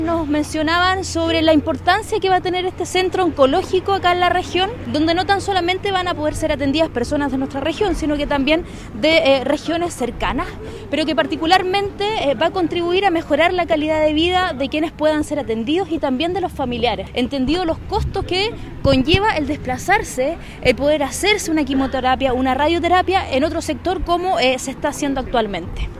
Por su parte, la delegada Regional de Los Lagos, Paulina Muñoz, afirmó que en el lugar “no tan solamente van a poder ser atendidas personas de nuestra región, sino que también de regiones cercanas”.